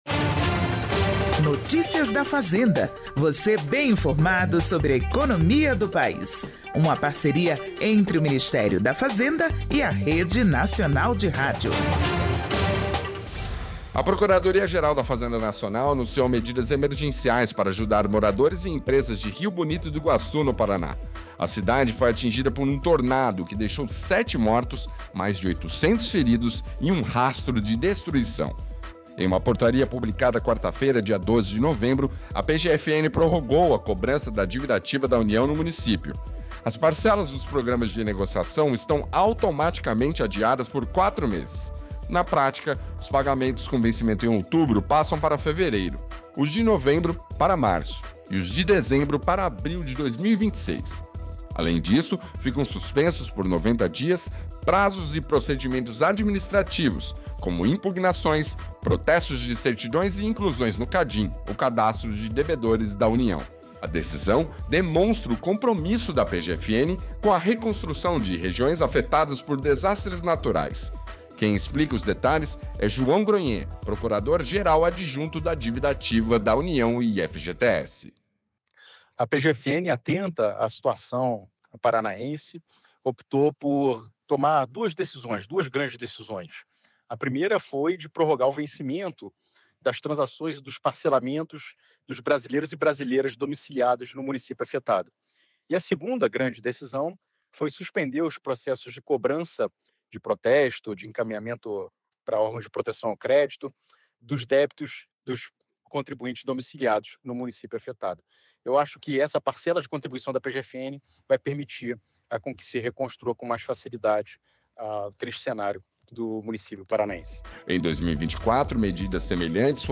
As inscrições continuam abertas, mas novos pedidos serão analisados em 180 dias. O secretário de Prêmios e Apostas do Ministério da Fazenda, Regis Dudena, explica.